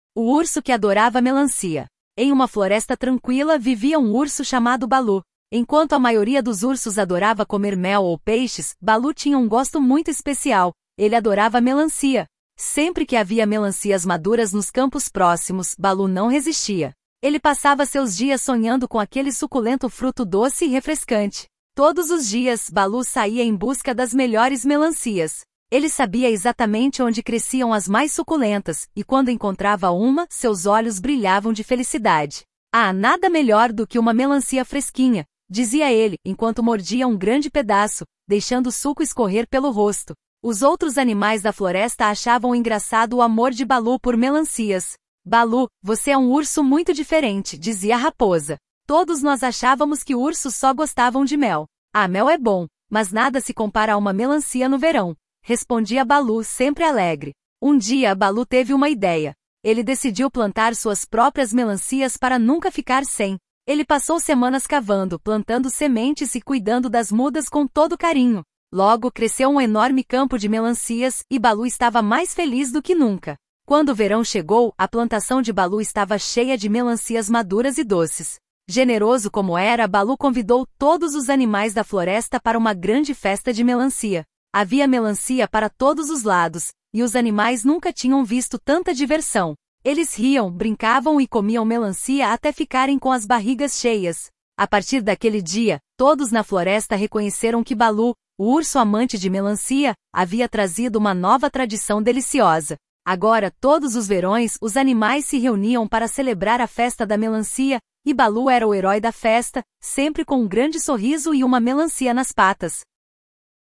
história de crianças urso